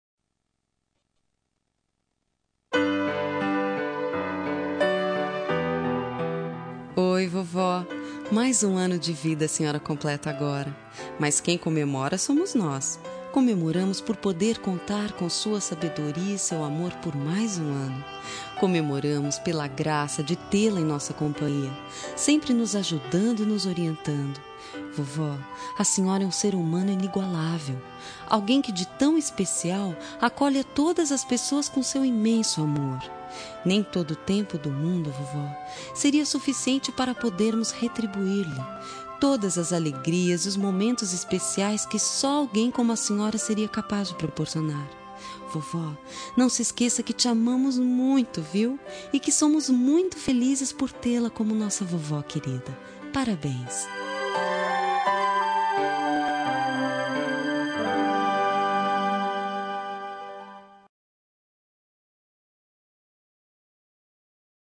Telemensagem Aniversário de Avó – Voz Feminina – Cód: 2058